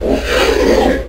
summon.wav